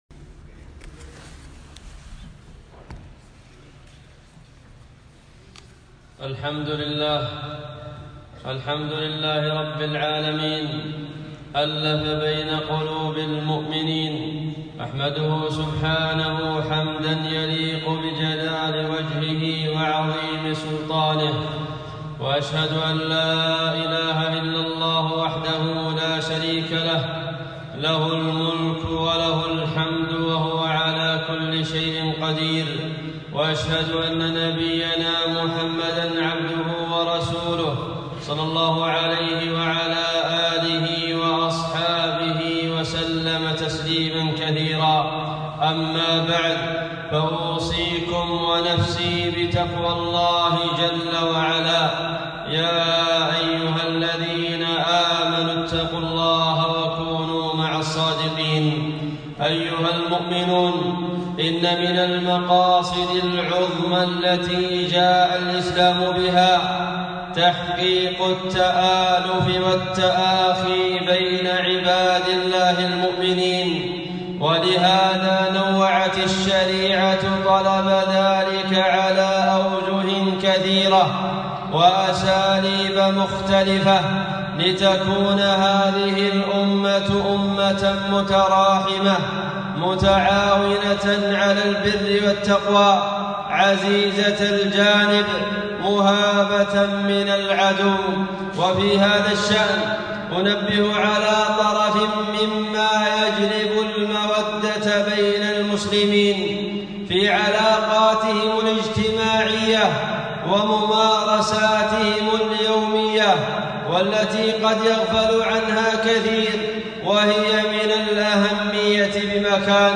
خطبة - الغيرة